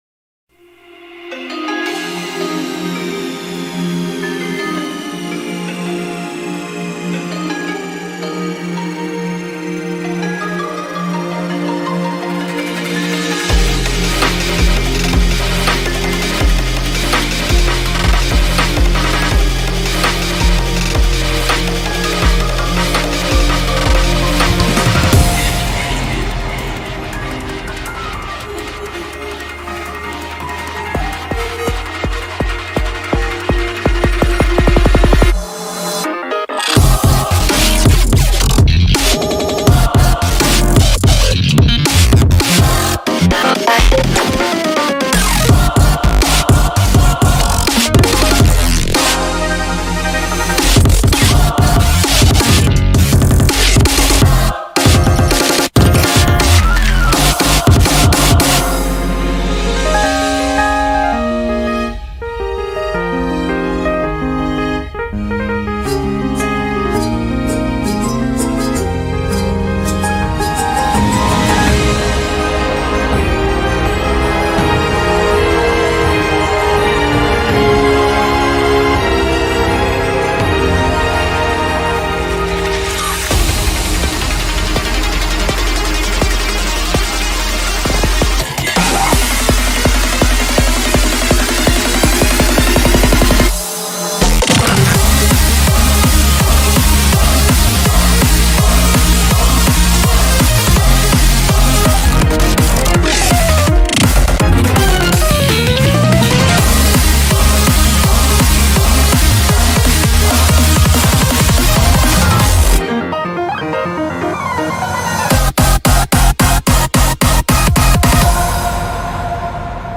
BPM83-165
Audio QualityPerfect (High Quality)
Commentaires[ART/BREAKS]